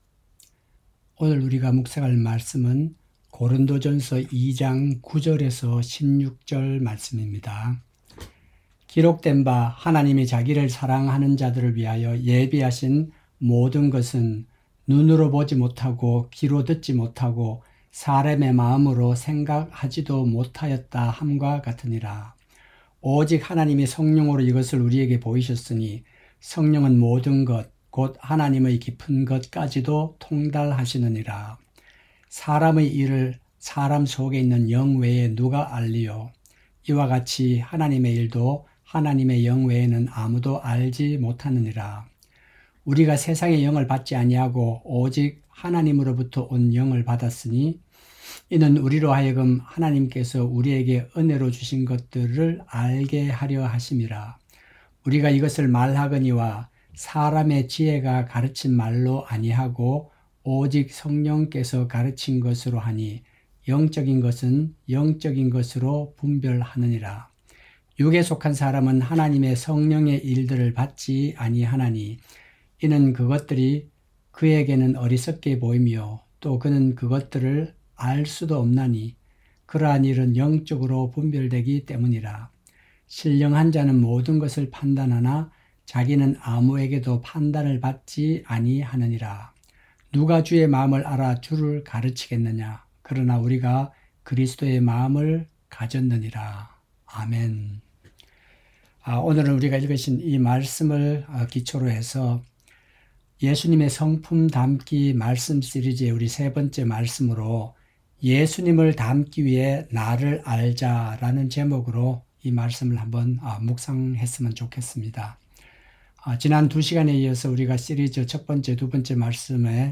새벽설교